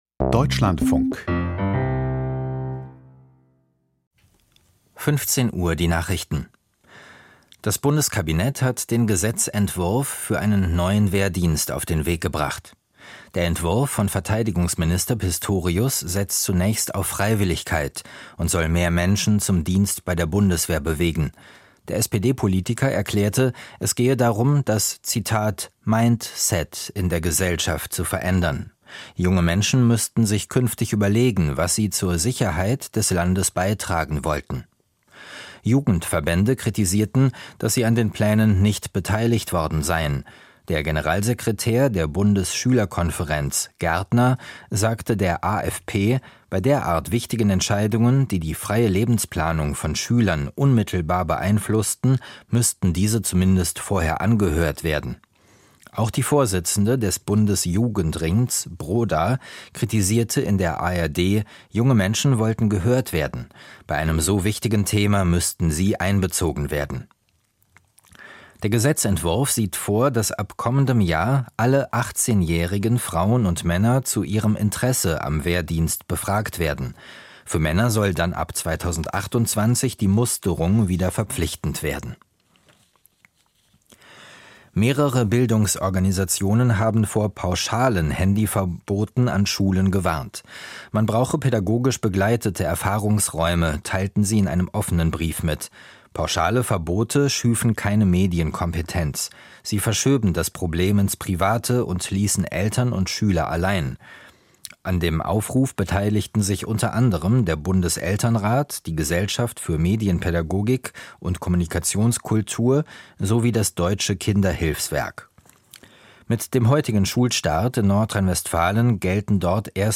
Die Nachrichten vom 27.08.2025, 15:00 Uhr
Aus der Deutschlandfunk-Nachrichtenredaktion.